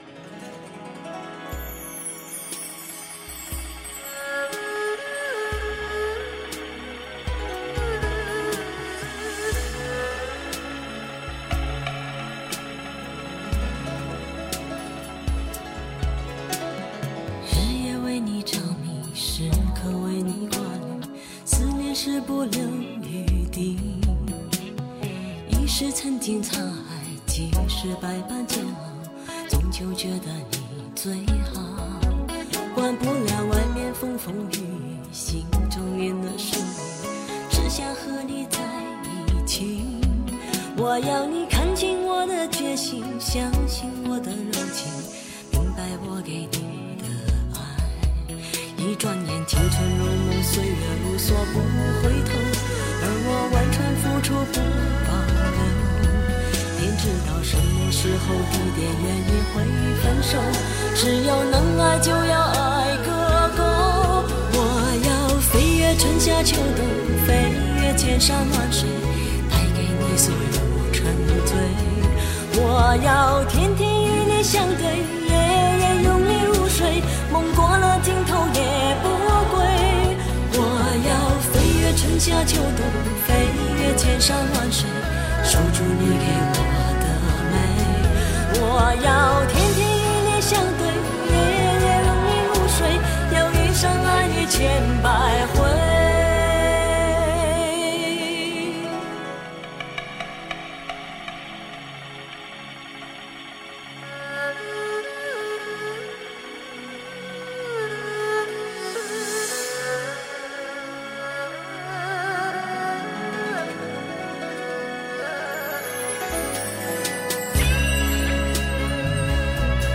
绝世伤感老情歌 演绎世间男女不解情缘 献给天下有情人
柔弱又坚强 浓浓的深情